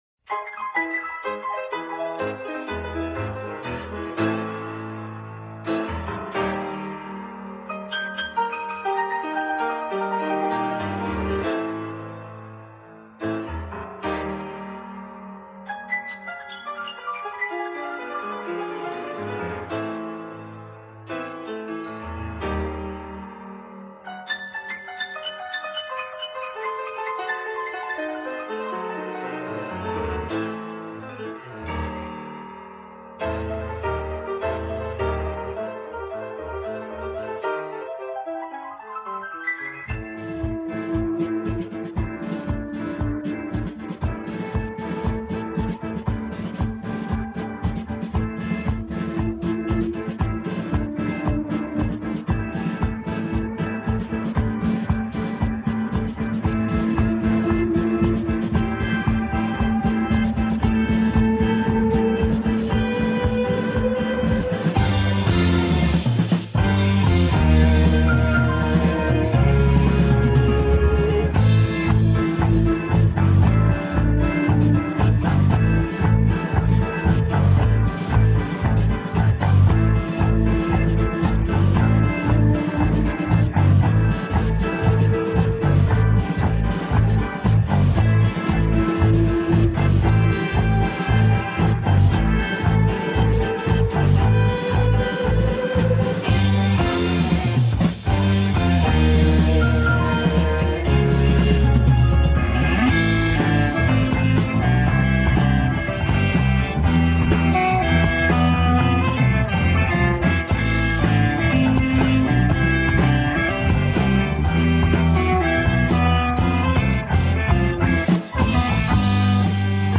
stereo, 4.0 Khz, 20 Kbps, file size: 391 Kb